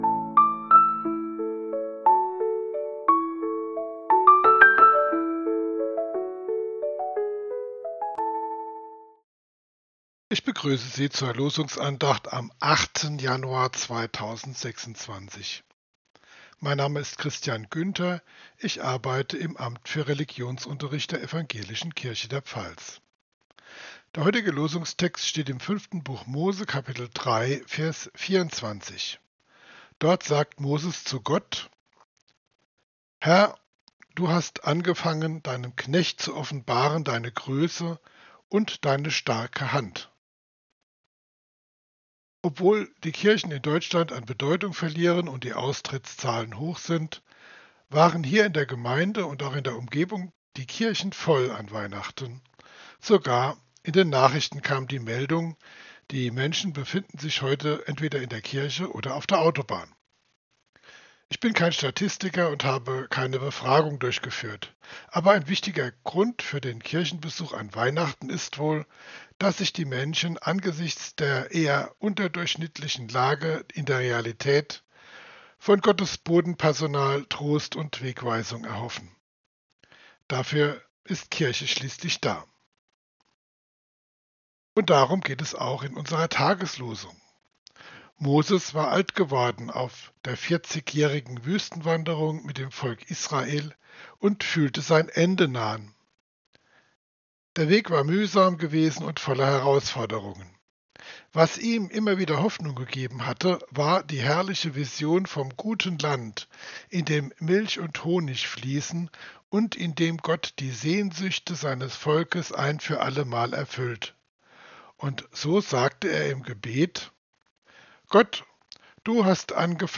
Losungsandacht für Donnerstag, 08.01.2026